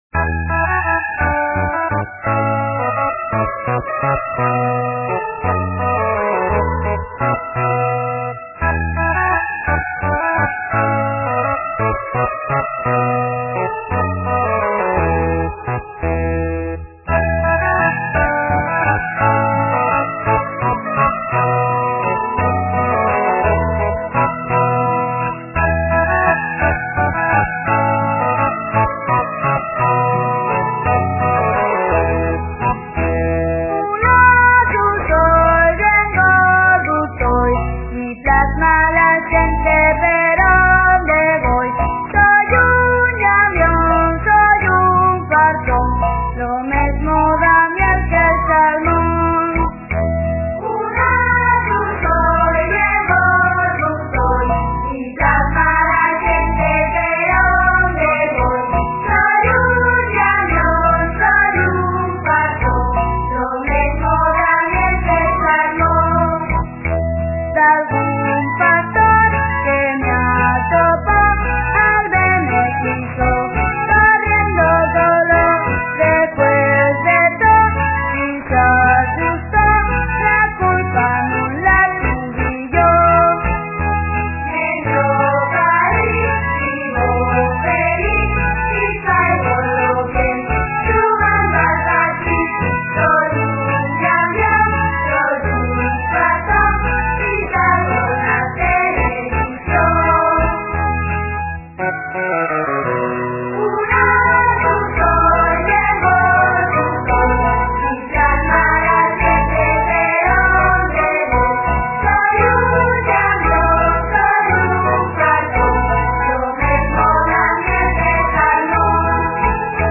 Son toos escolinos del C.P de Lieres. (Siero) La música
guitarres